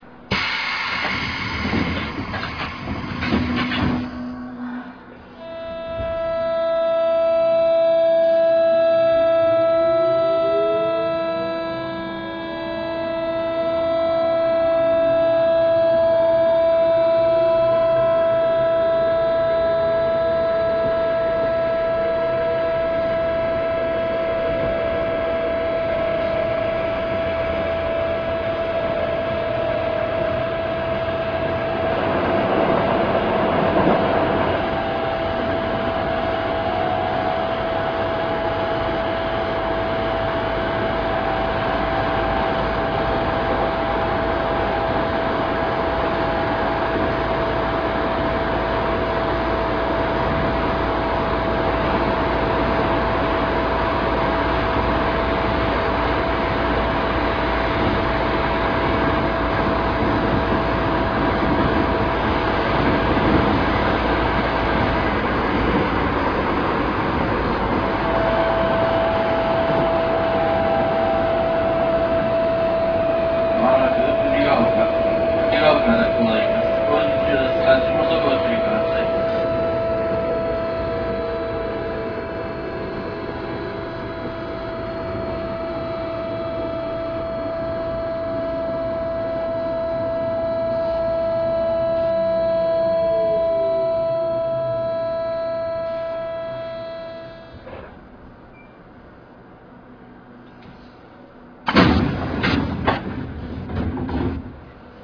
走行音(三菱)[sub80cma.ra/RealAudio3.0-28.8 Mono, full response/205KB]
録音区間：東急田園都市線市が尾→藤が丘
種類：電機子チョッパ制御(三菱・日立、2相2重サイリスタチョッパ、AVF方式)